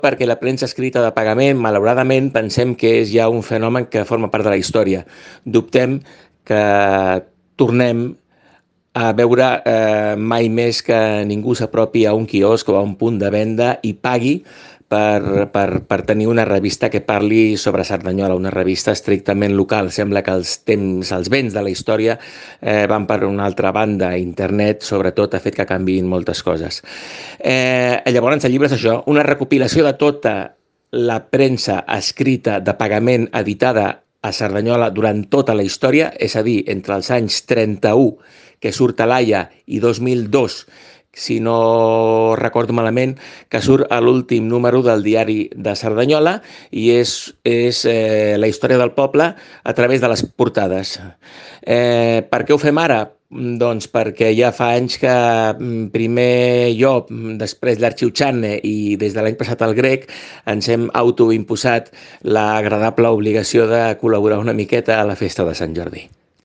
Declaracions